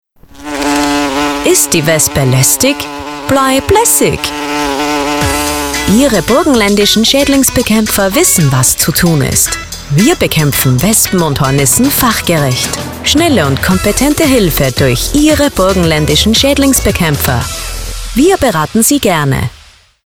Radiospots der Landesinnung
radiospot-schaedlingsbekaempfer.mp3